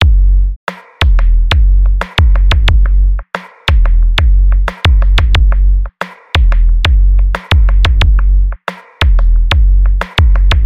催眠跳的鼓点
描述：HIP HOP CHILL
标签： 90 bpm Hip Hop Loops Drum Loops 1.80 MB wav Key : Unknown
声道立体声